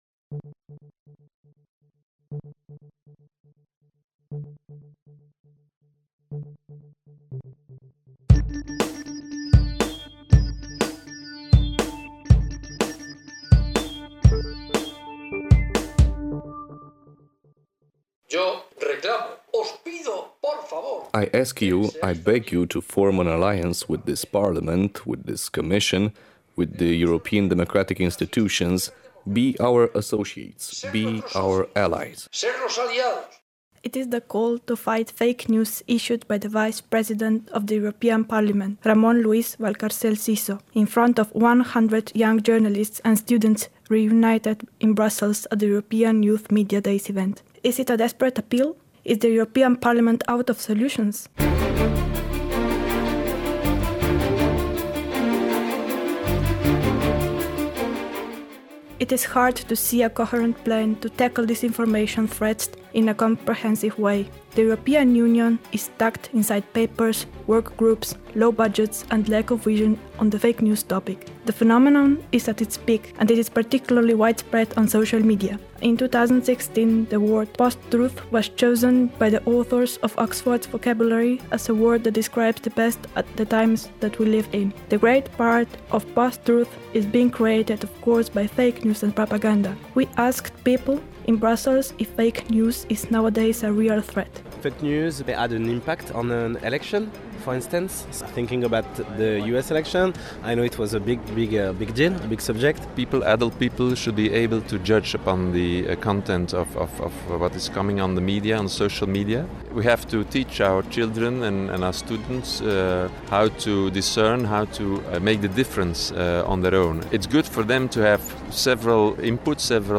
Reportajul în limba engleză realizat în cadrul EYMD: